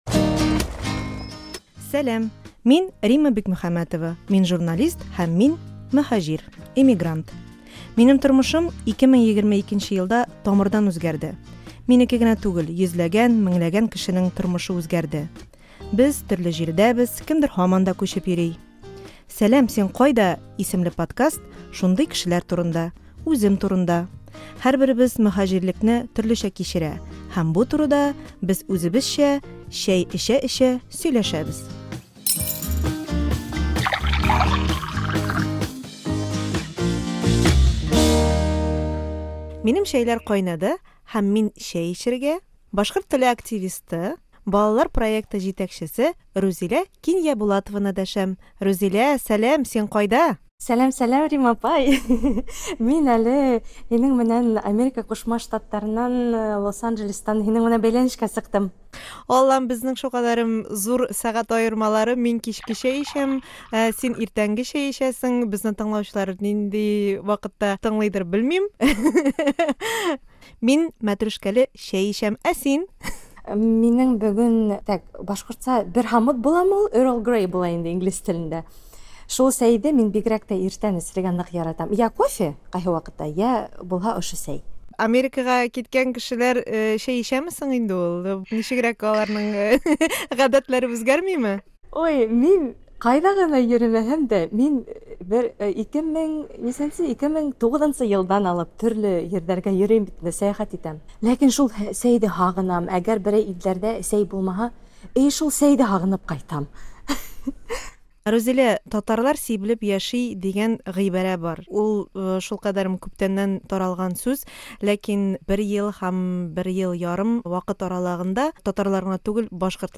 "Сәлам, син кайда?" татарча яңа подкастның беренче чыгыралышында Истанбул белән Лос-Анджелес мөһаҗирләре сөйләшә.